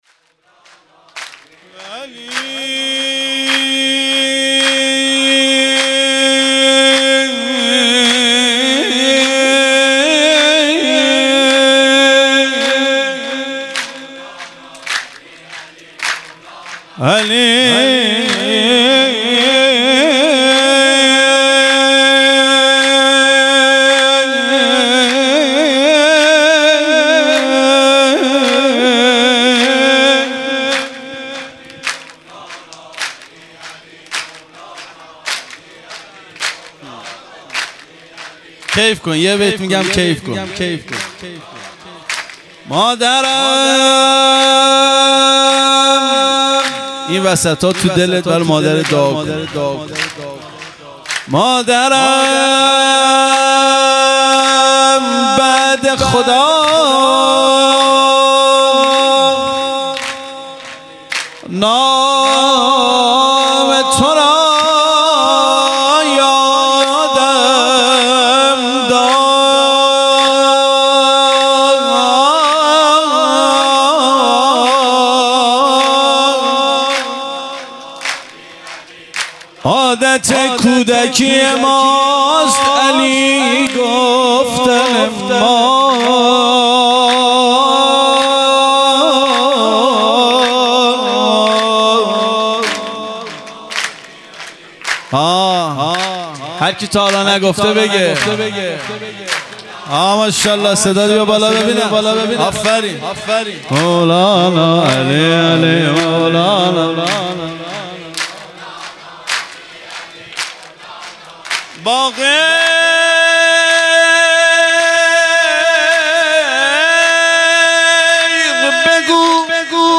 سرود| مادرم بعد خدا نام تورا یادم داد مداح
جشن عید غدیر